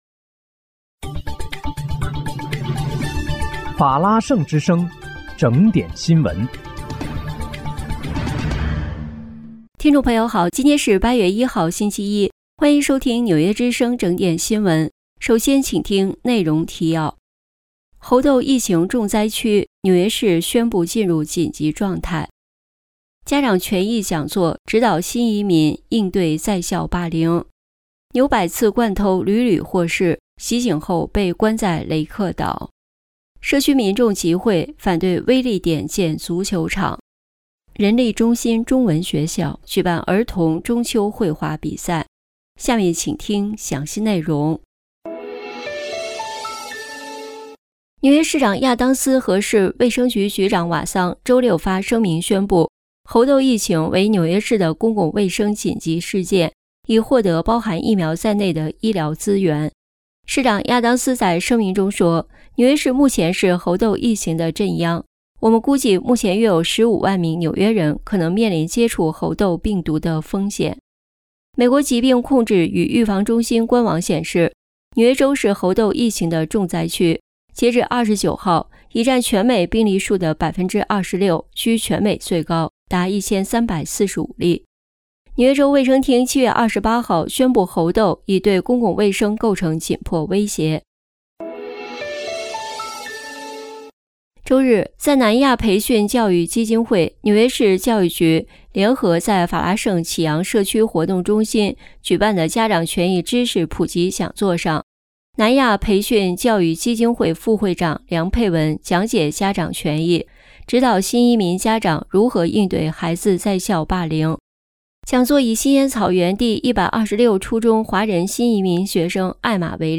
8月1日（星期一）纽约整点新闻